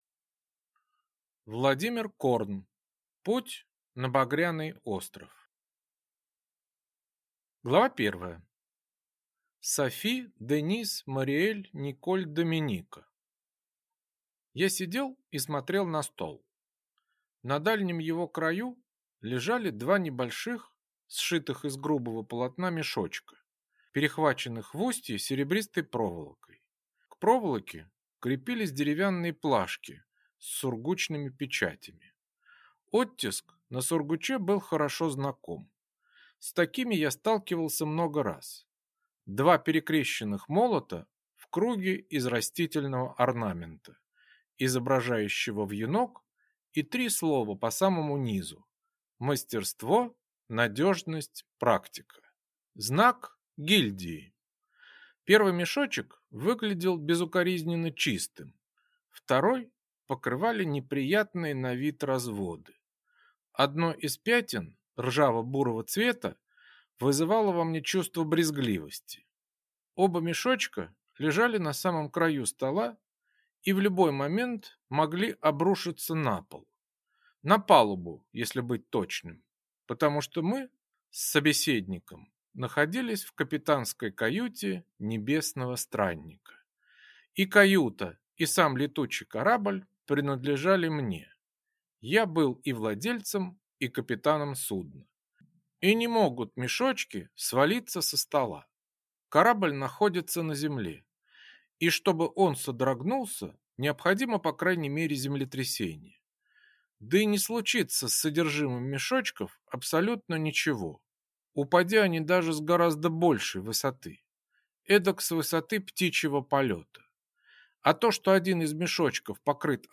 Аудиокнига Путь на Багряный остров | Библиотека аудиокниг